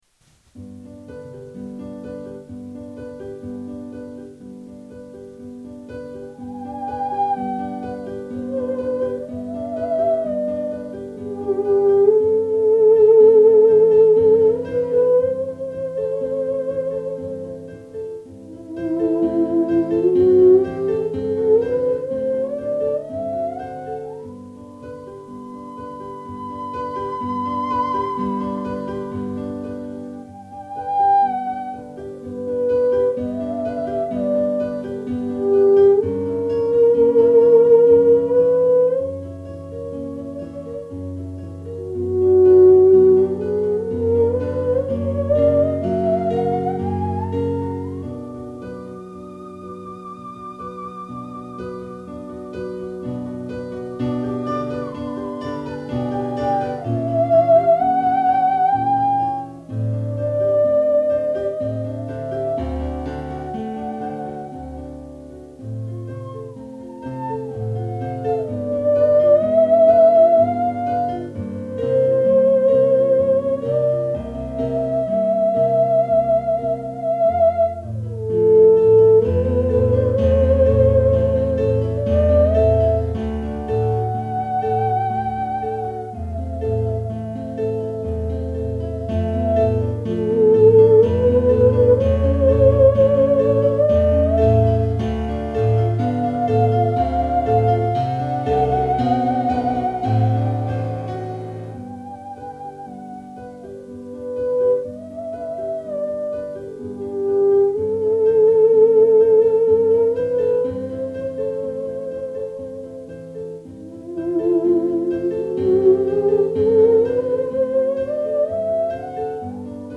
Möglicherweise das Theremin.